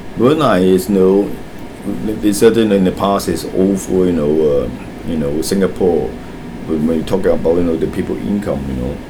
S1 = Taiwanese female S2 = Hong Kong male
Heard as : all full
One problem is the close back vowel in the second syllable of over , so it sounds like [ʊ] ; in addition, the word seems to be stressed on the second syllable.